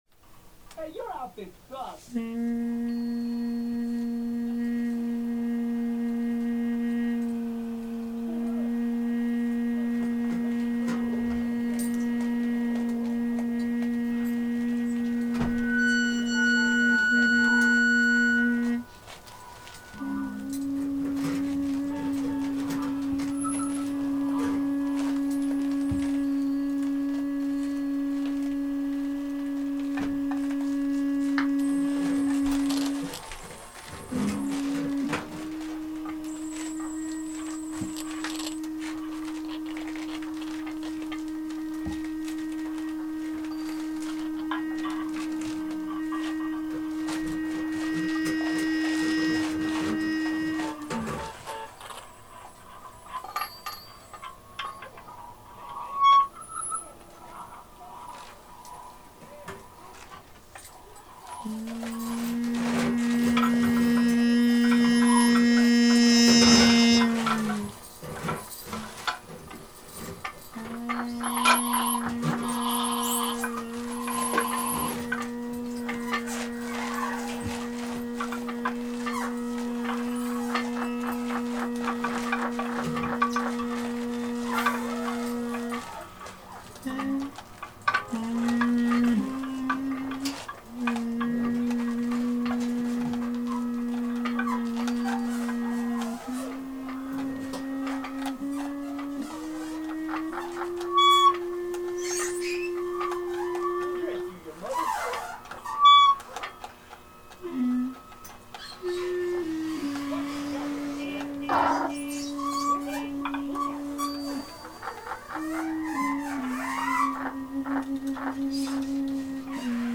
Location: Backyard Filmfest